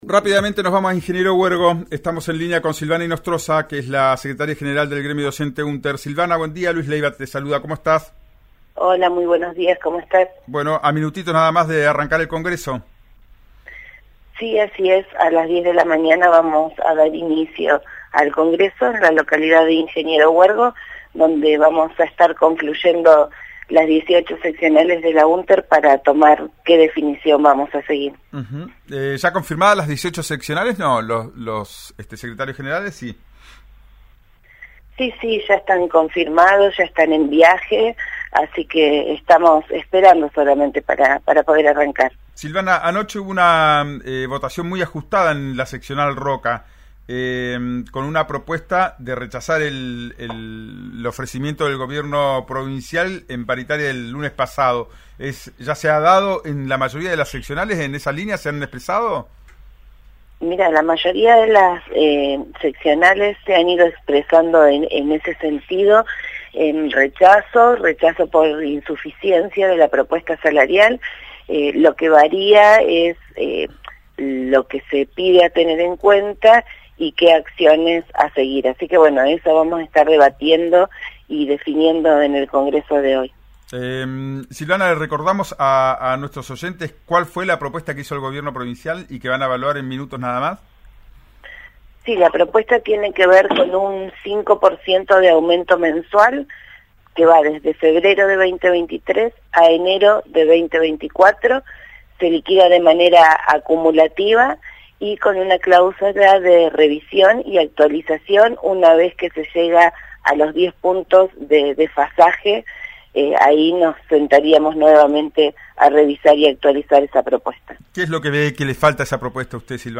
en «Ya es tiempo» por RÍO NEGRO RADIO: